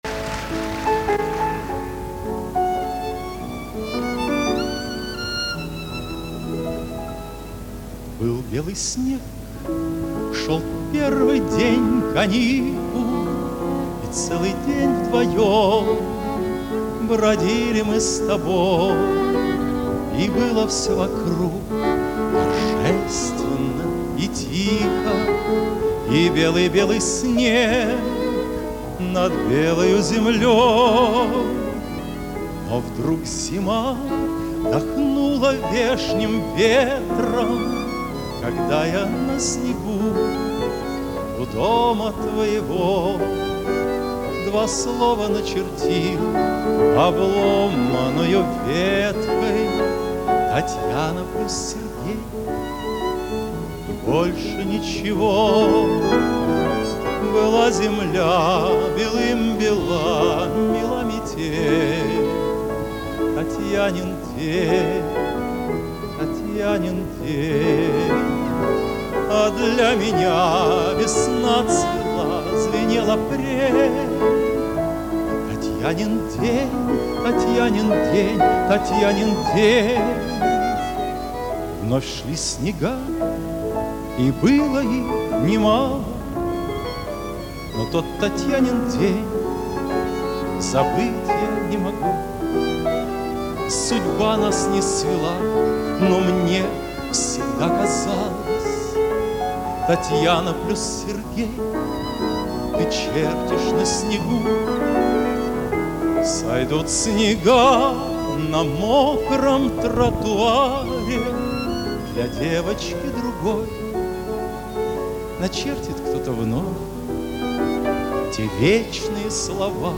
Композиторы-песенники
Режим: Mono